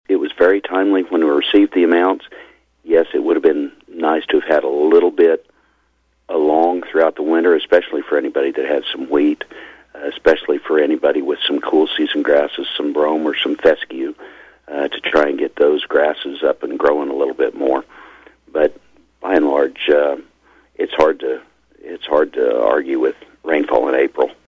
on KVOE’s Morning Show this week.